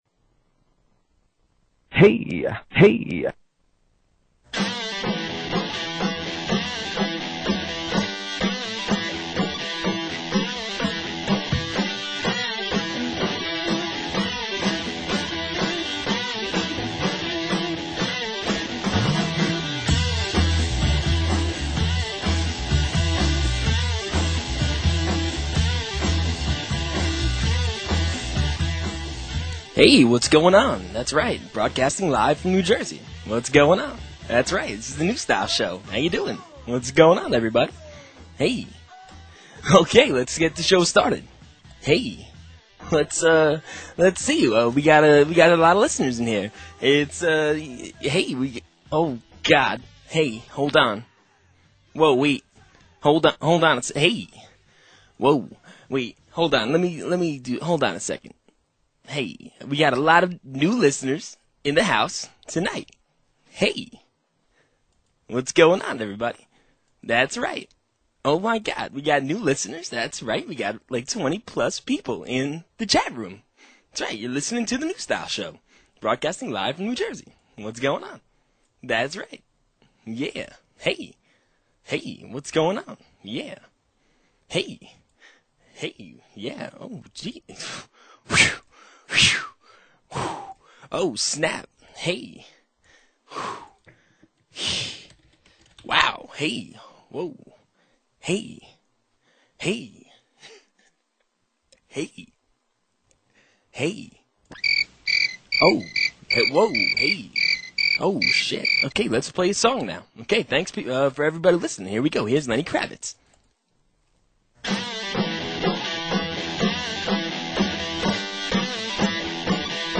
Because of this, I decided to imitate his awful show.